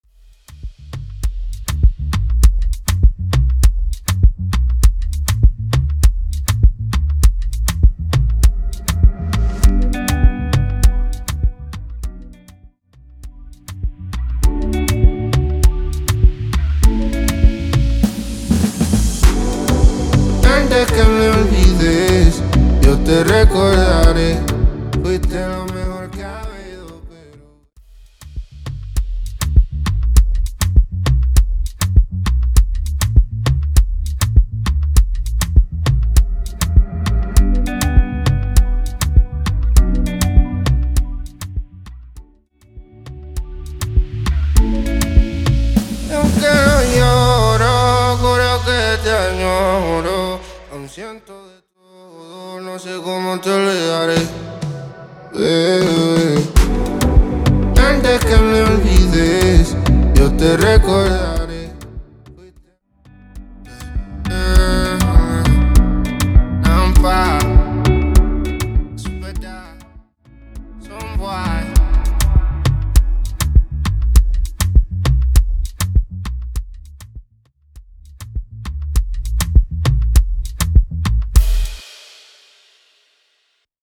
Intro Dirty, Intro Acapella Dirty